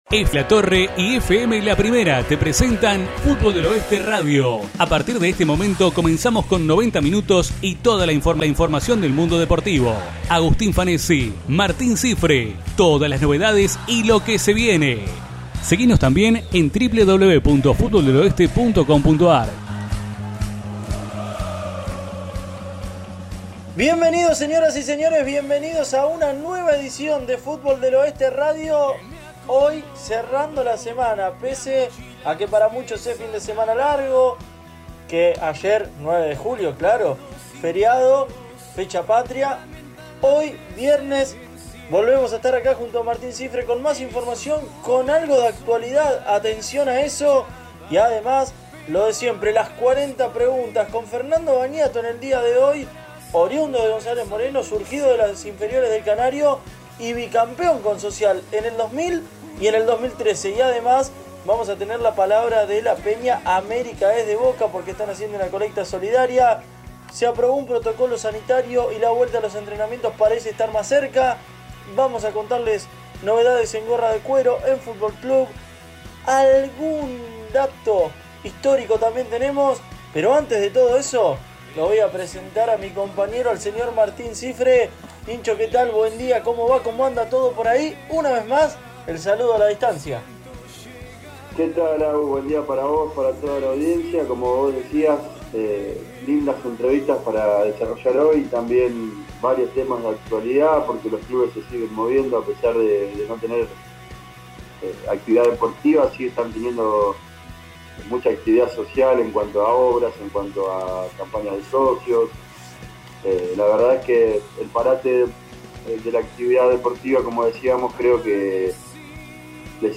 Nuestro programa radial tuvo su 38ª edición de este 2020, aquí te acercamos los 2 bloques para que puedas escucharlo a través de tu computadora o dispositivo móvil.